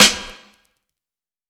Dilla Snare 30.wav